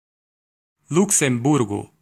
Ääntäminen
Vaihtoehtoiset kirjoitusmuodot Luxemburg Luxemburg City Lëtzebuerg Ääntäminen US : IPA : /ˈlʌk.səm.bɝɡ/ UK : IPA : /ˈlʌk.səm.bɜːɡ/ Lyhenteet ja supistumat (laki) Lux. Haettu sana löytyi näillä lähdekielillä: englanti Käännös Ääninäyte Erisnimet 1.